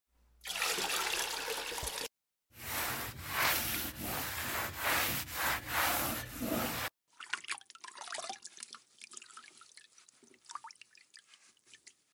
Cleaning ASMR, floor scrubbing.